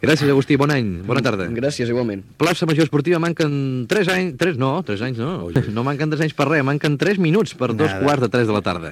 Identificació del programa i errada en el moment de donar l'hora
Esportiu